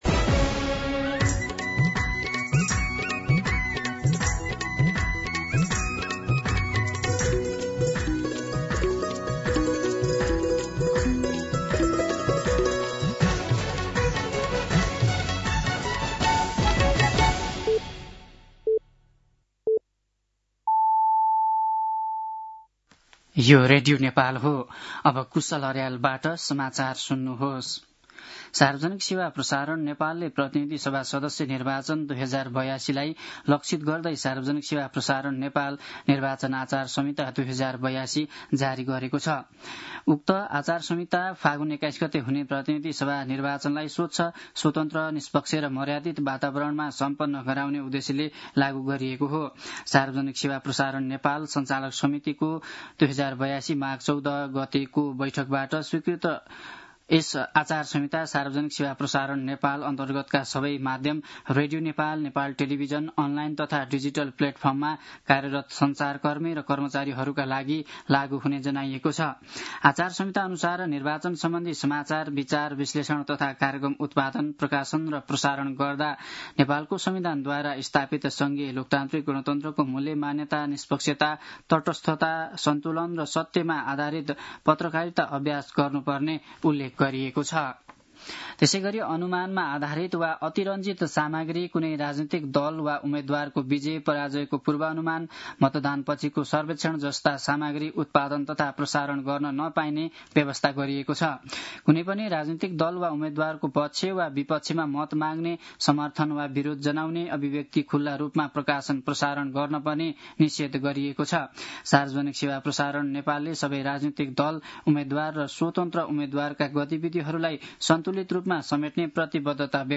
दिउँसो ४ बजेको नेपाली समाचार : १४ माघ , २०८२